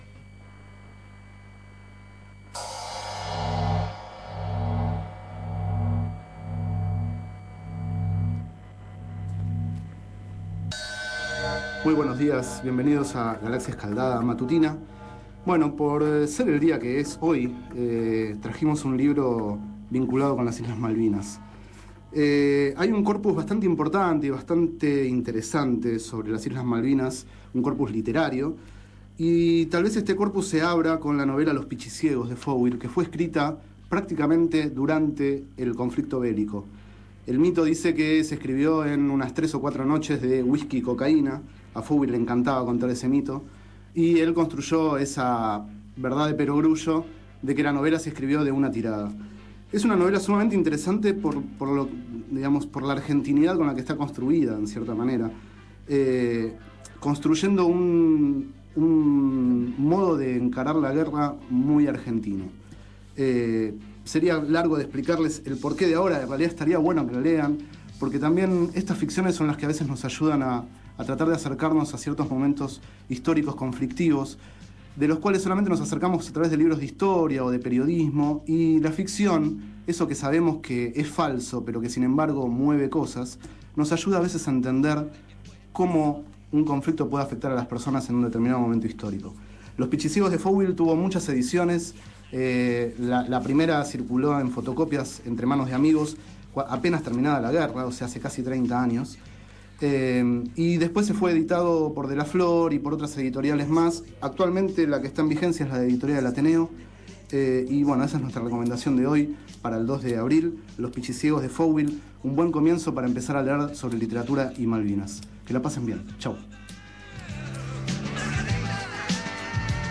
Este es el 5º micro radial, emitido en el programa Enredados, de la Red de Cultura de Boedo, por FMBoedo, realizado el 2 de abril de 2011, sobre el libro Los Pichiciegos, de Fogwill.